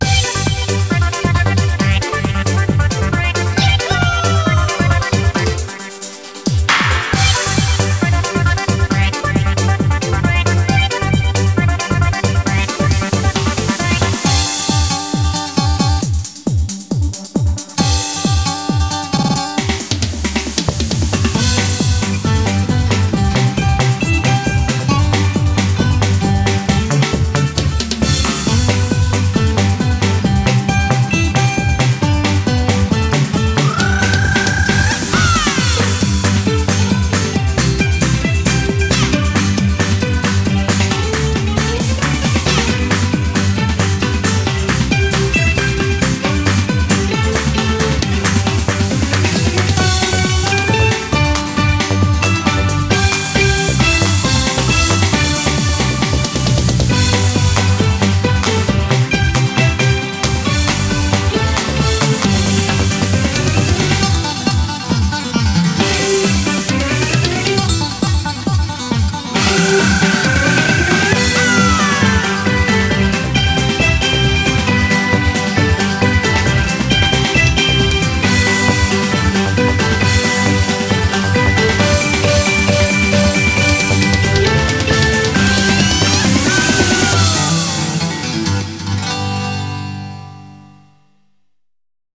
BPM135
Audio QualityPerfect (High Quality)
Better quality audio.